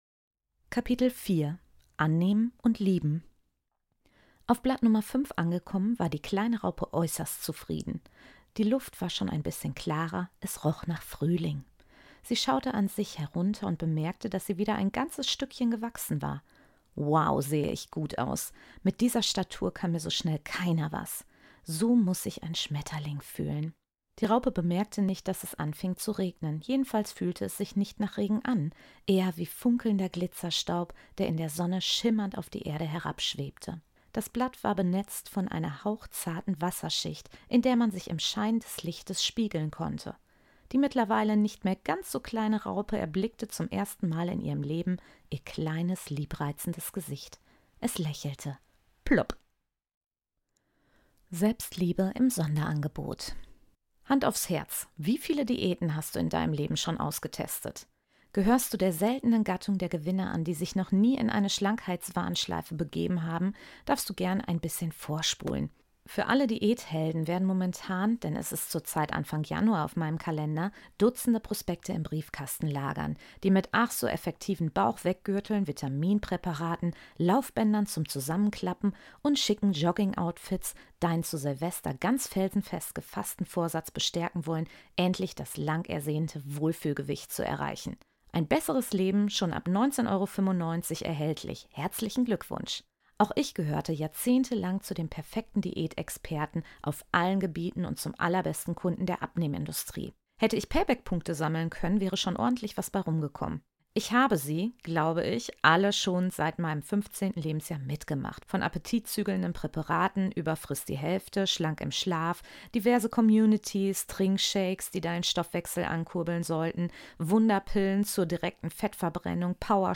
Loslassen, träumen, genießen – genau das erwartet dich in diesem Hörbuch. Es ist meine liebevolle Einladung, dich zurückzulehnen und dich auf eine bunte, transformierende Reise zu deinem wahren ICH zu begeben.